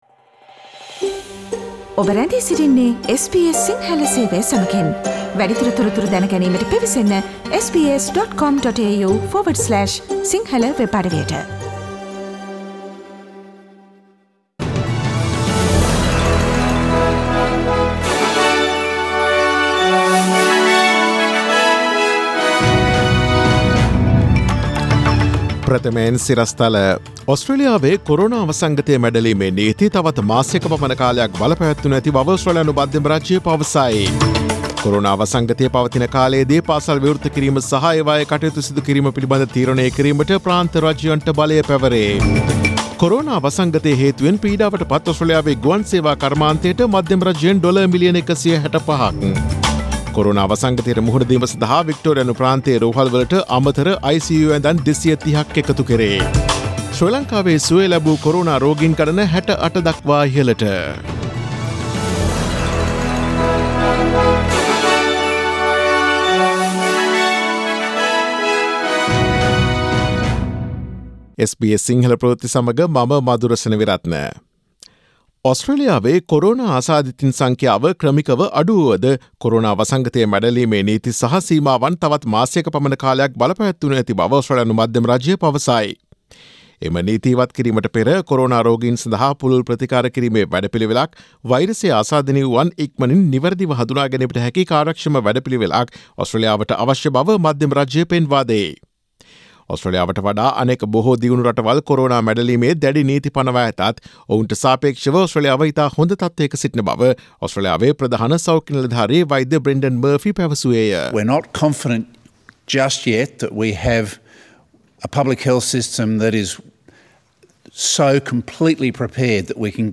Daily News bulletin of SBS Sinhala Service: Friday 17 April 2020